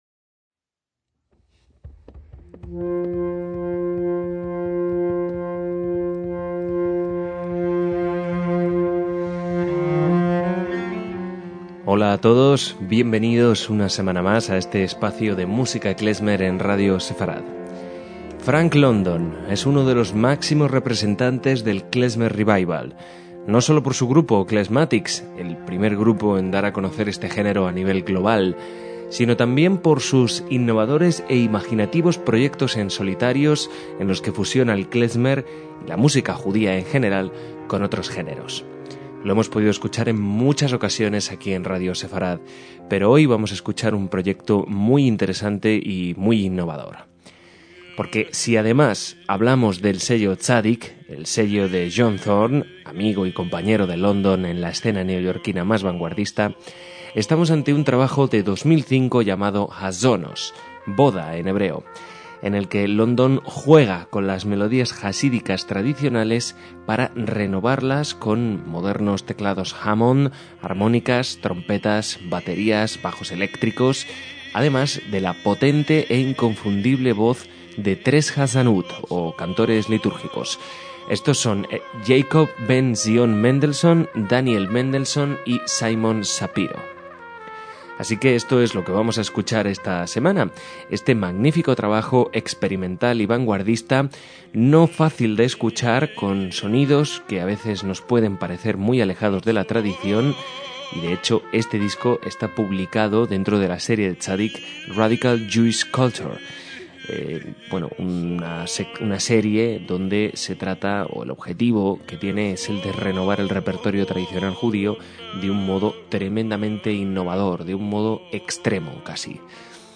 trompeta
sección rítmica